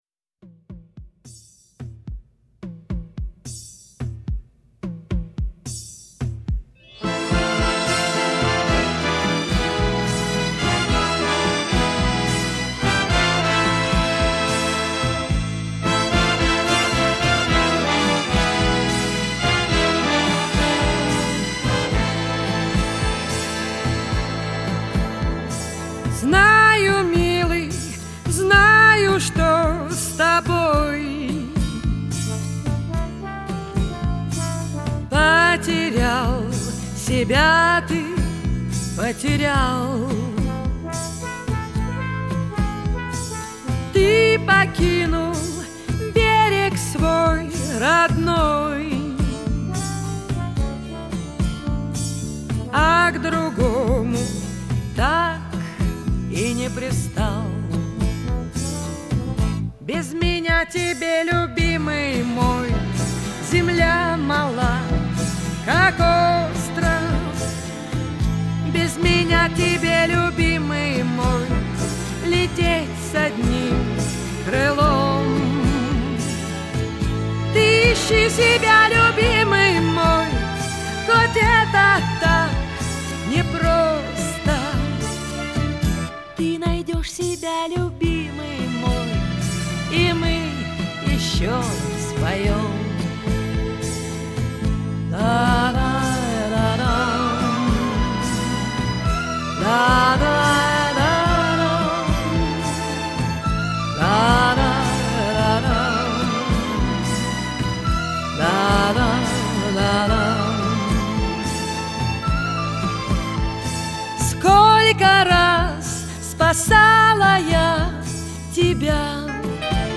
Популярная музыка [41]